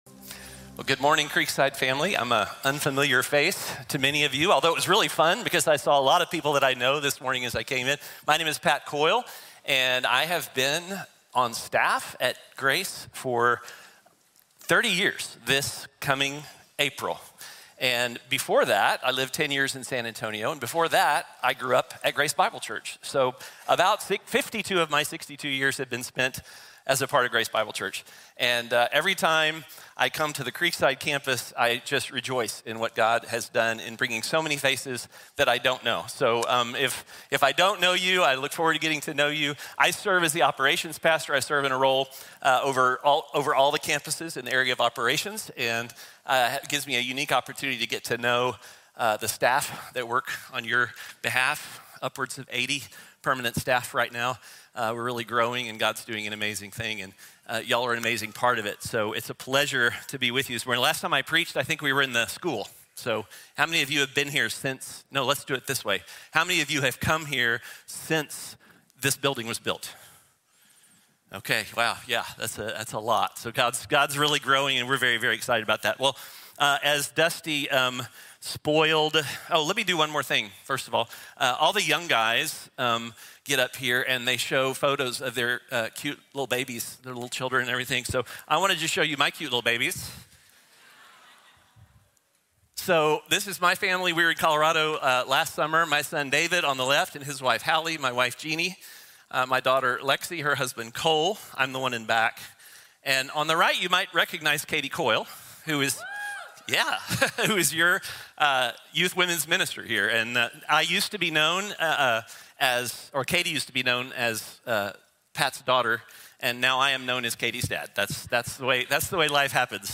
Joseph | Sermón | Iglesia Bíblica de la Gracia